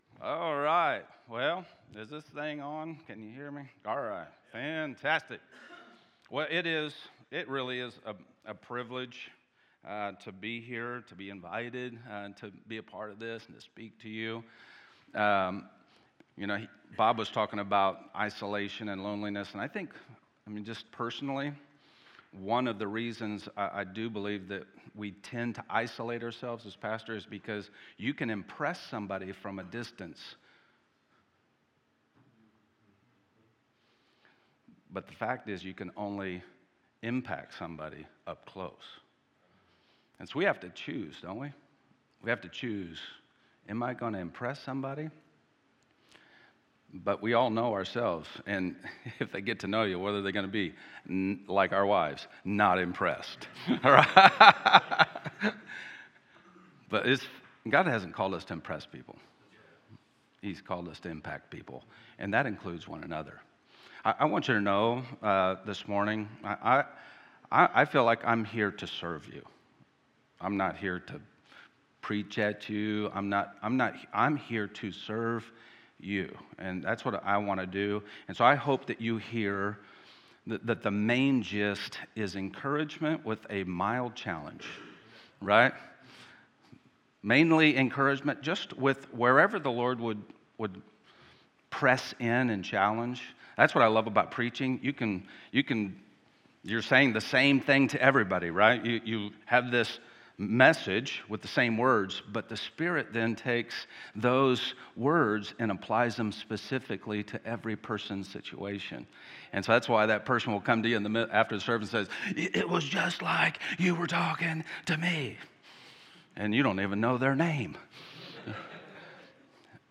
Main Session Guest Speaker